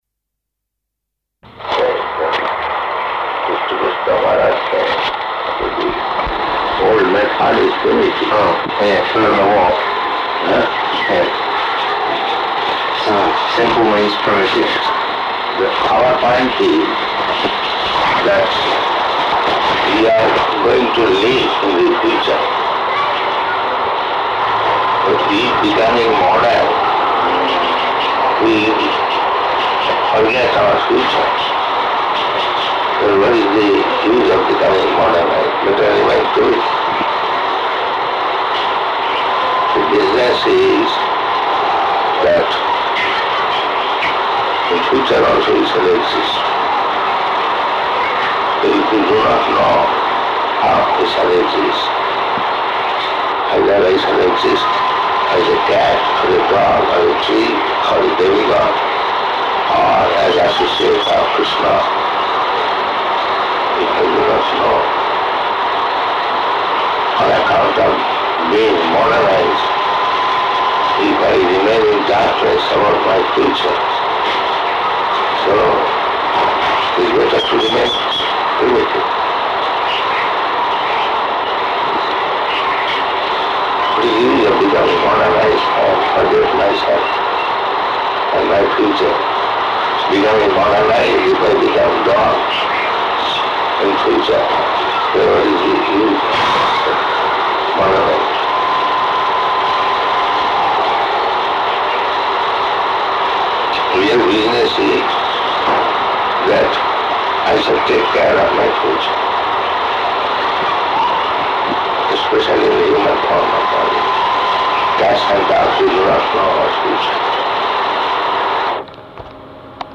Room Conversation
Room Conversation --:-- --:-- Type: Conversation Dated: May 3rd 1976 Location: Fiji Audio file: 760503R1.FIJ.mp3 Prabhupāda: ...they used to discover, whole method is finished.